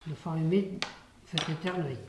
Saint-Urbain
Langue Maraîchin
Catégorie Locution